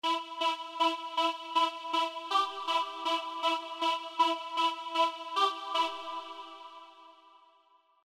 Hab das auch mal geschraubt. Saw Oszillator, dick Unison drauf, Formant Filter, und das wars schon.